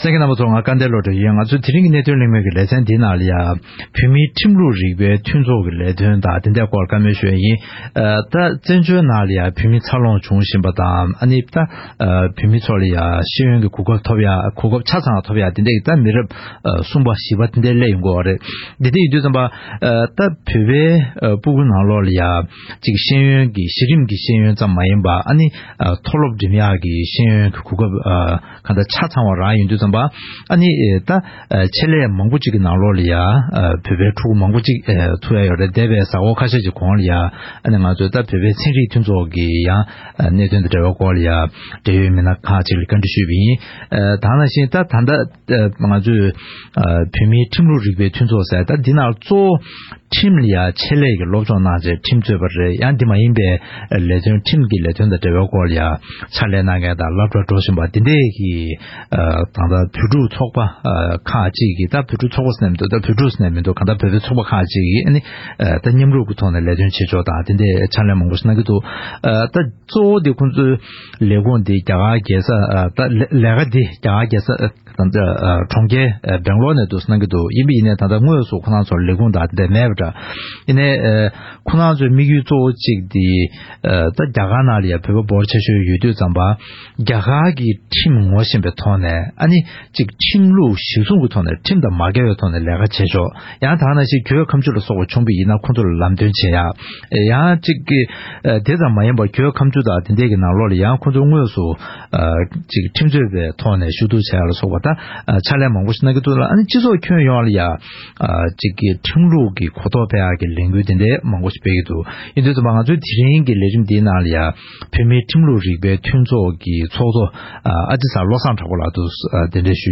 ༄༅། །བོད་མིའི་ཁྲིམས་ལུགས་རིག་པ་བའི་ཚོགས་པ་ཐོག་མར་གསར་འཛུགས་གནང་སྟངས་དང་བར་དུ་ལས་དོན་གནང་ཕྱོགས་སོགས་ཀྱི་སྐོར་འབྲེལ་ཡོད་དང་གླེང་མོལ་ཞུས་པ་ཞིག་གསན་རོགས་གནང་།།